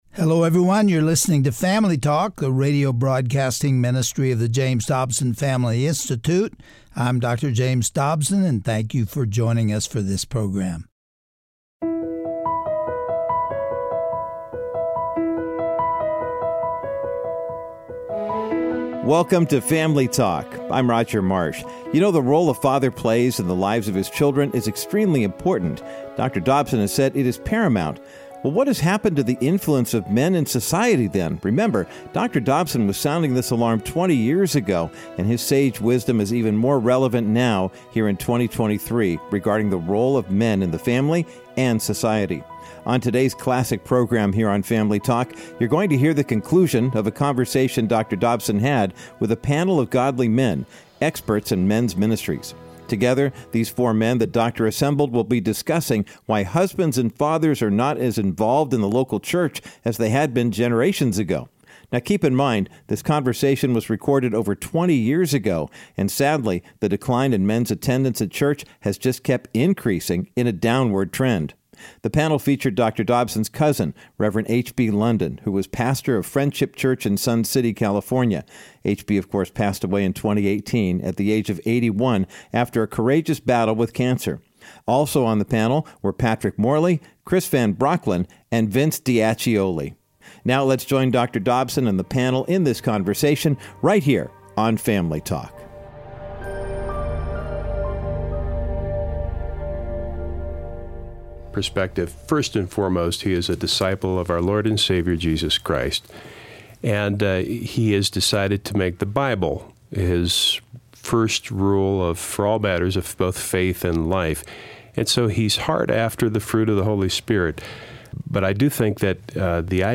On today’s edition of Family Talk, Dr. James Dobson continues his discussion with a panel of experts in men’s ministry on the subject of biblical masculinity. The panel also talks about three simple steps in ministering to men, and why these are imperative for the future of the Church.